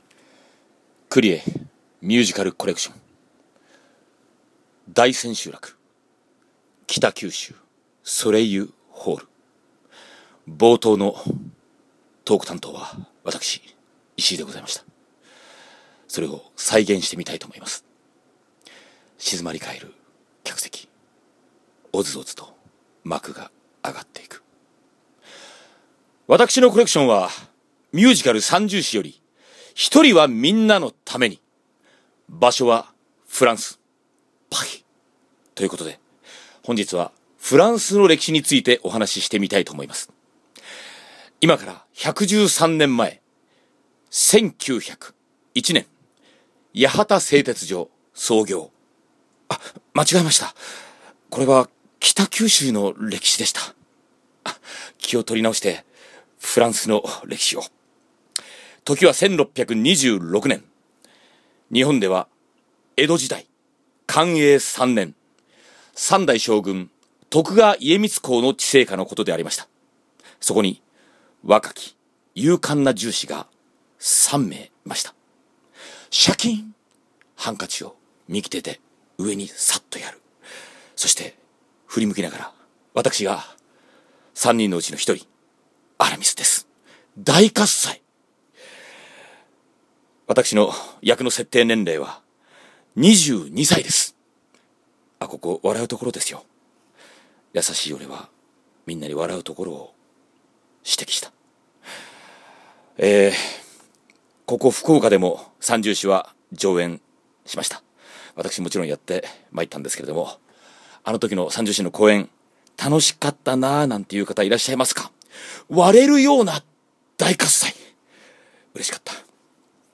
こんな感じというのをト書き(実況)も入れながら忠実に再現し、来られなかった皆様へのプレゼントとさせていただきたい…そんなことを考え実行する俺は…やはり変態でしょうか(笑)？
冒頭トーク 大千秋楽 北九州ソレイユホール
０：３５あたりの「･･･場所はフランス、Paris･･･」のおフランス語ちっくな発音。